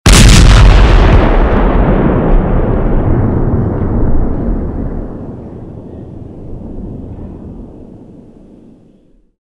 Реалистичные эффекты с хорошей детализацией низких частот.
Разнообразные звуки выстрелов пушечных орудий в mp3 для монтажа видео на YouTube, игр и приложений